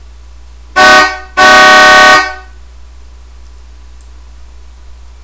ホーン
この音色なら低くてもいいのですがねぇ...